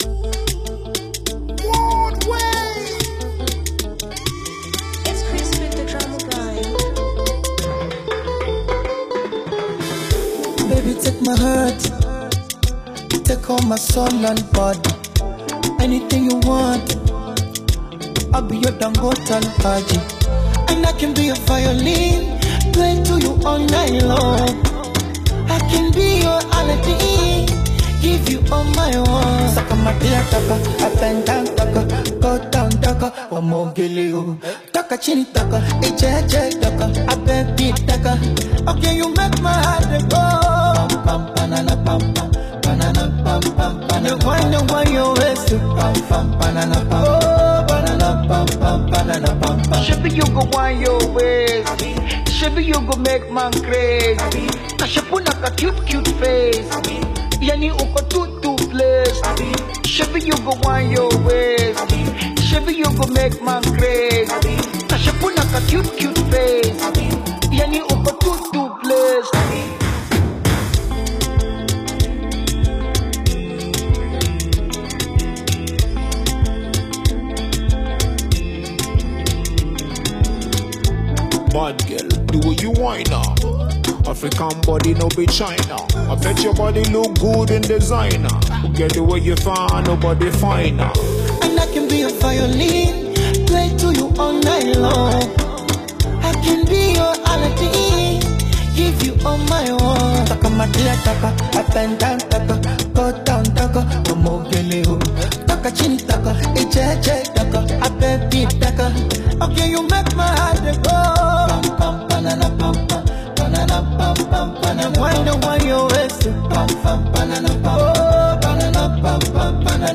African Music You may also like